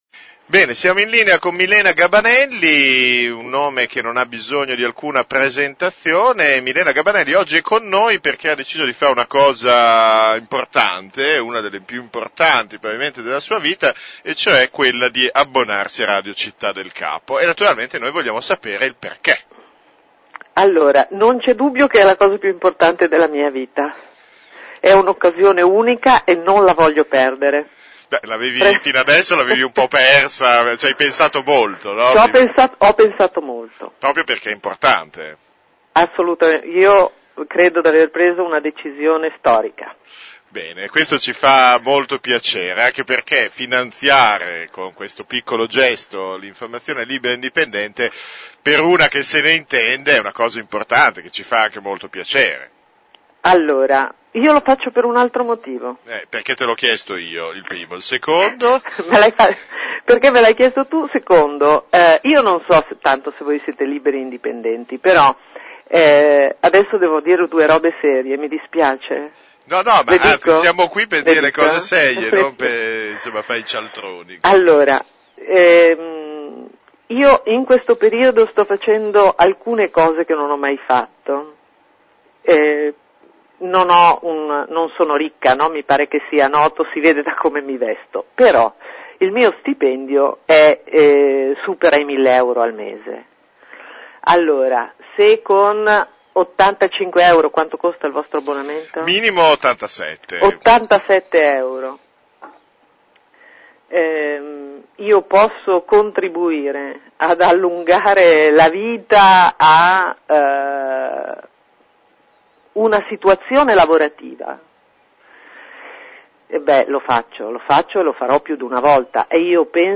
Proprio mentre la campagna abbonamenti entra nel vivo, la giornalista di Report ha scelto di sostenere il progetto della nostra emittente e si è abbonata alla radio e al quotidiano on line. Ascolta l’estratto dell’intervista milenasito